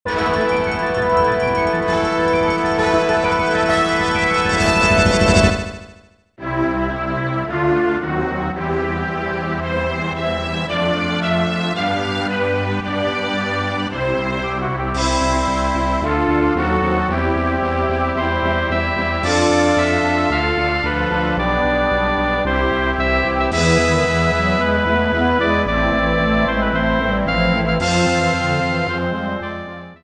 a triumphant orchestral rendition